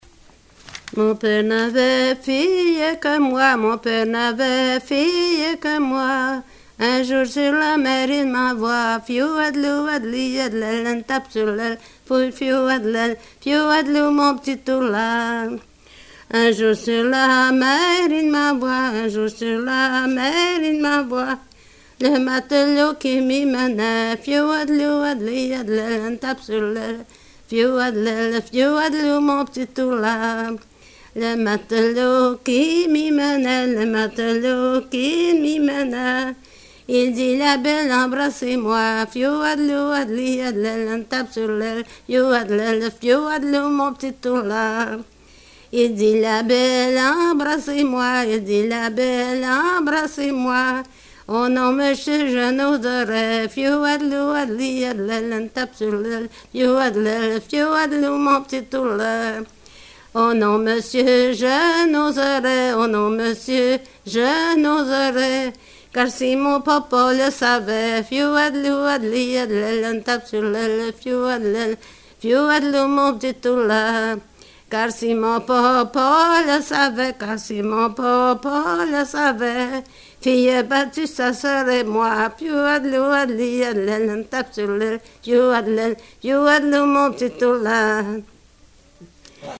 Folk Songs, French--New England
sound cassette (analog)